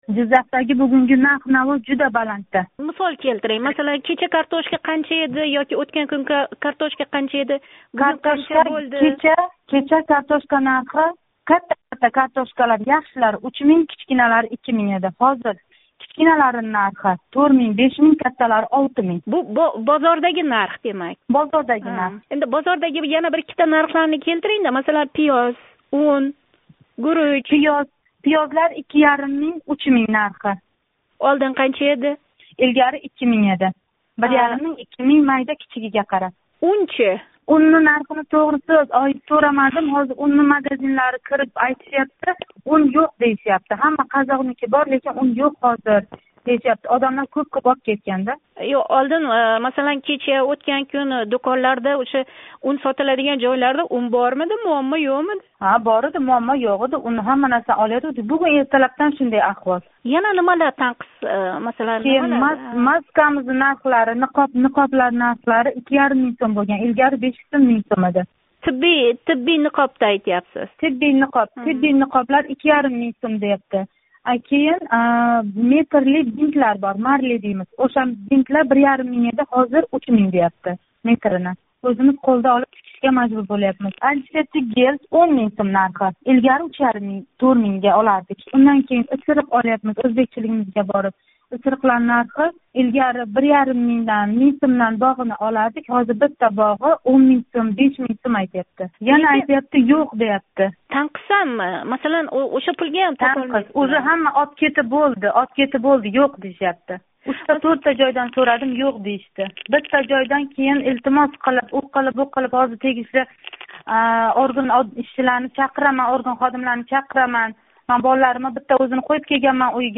Муҳтарам муштарий, агар сизни ташвишга солаётган муаммолар бўлса, шунингдек, ўзингиз гувоҳи бўлаётган воқеа-ҳодисалар борасида Озодликка хабар бермоқчи бўлсангиз¸ бизга қўнғироқ қилинг.